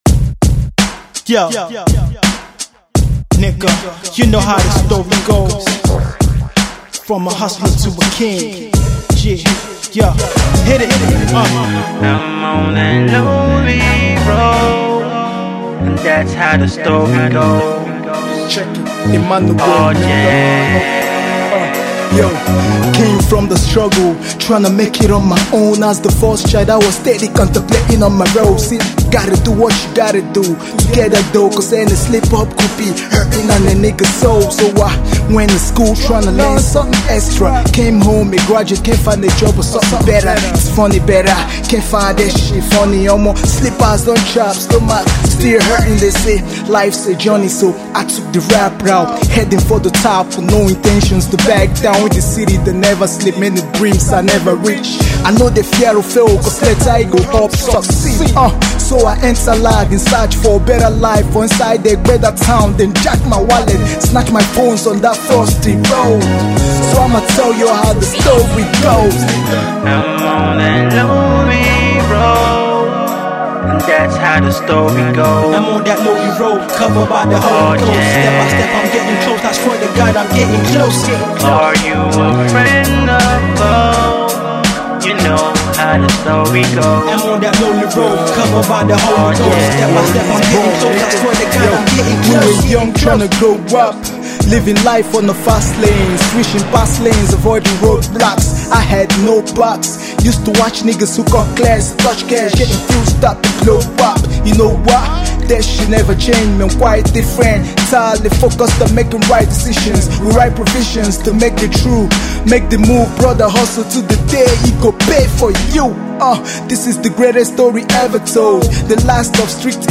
its a solid Rap song